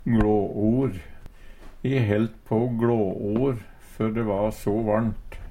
DIALEKTORD PÅ NORMERT NORSK gLå or svime av, miste pusten Infinitiv Presens Preteritum Perfektum Eksempel på bruk E helt på å gLå or før de va so varnt.